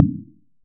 impactMetal_000.ogg